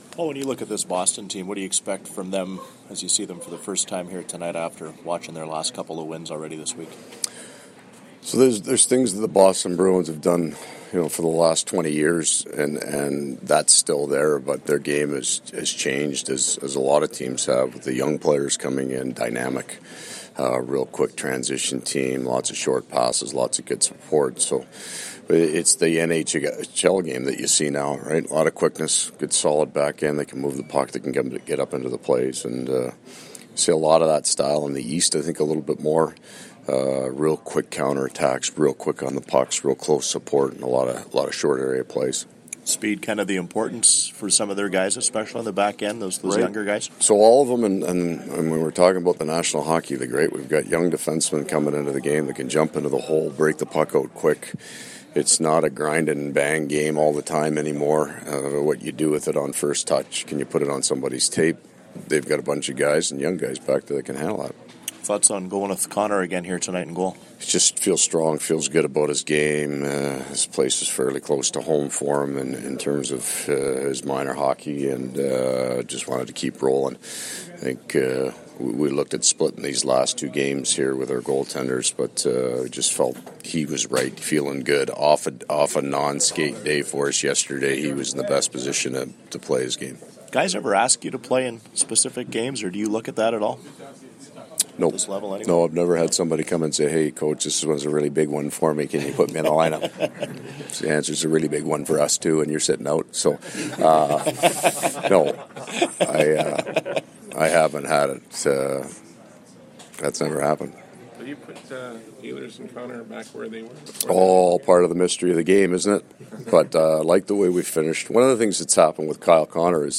Pre-game audio:
Audio courtesy of TSN 1290 Winnipeg.